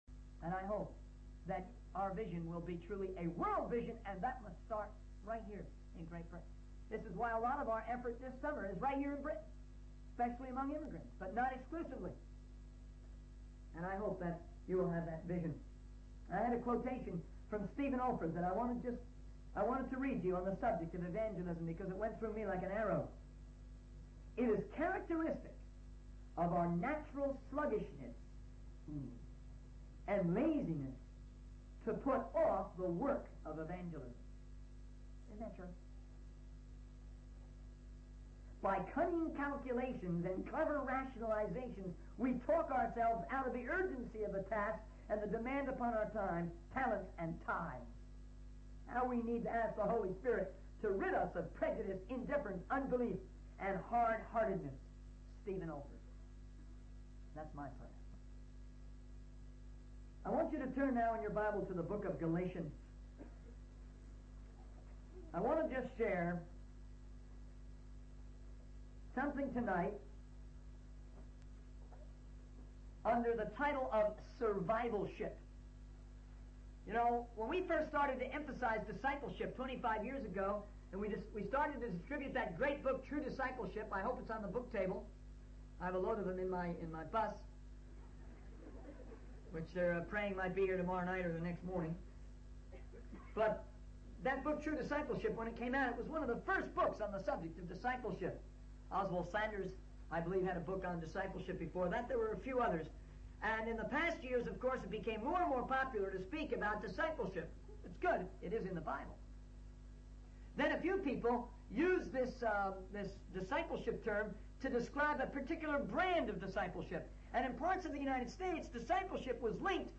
In this sermon, the speaker addresses the issue of passivity and spectatorism that has infiltrated the church.